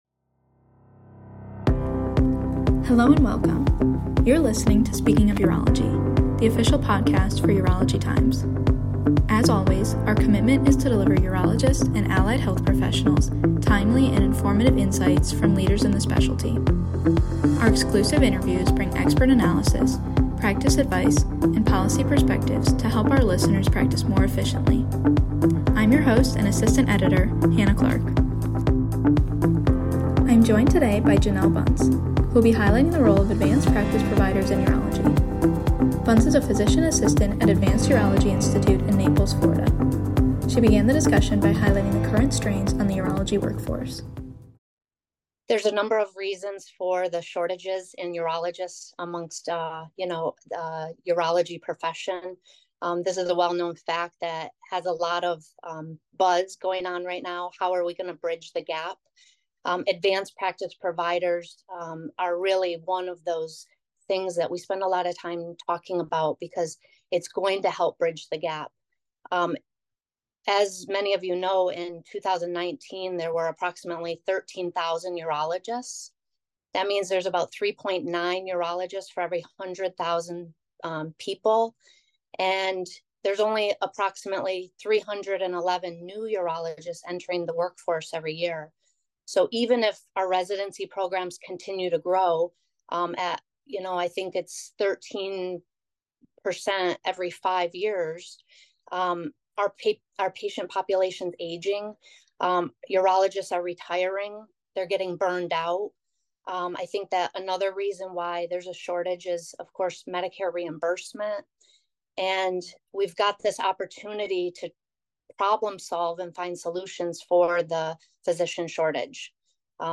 Headliner Embed Embed code See more options Share Facebook X Subscribe In this interview